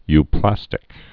(y-plăstĭk)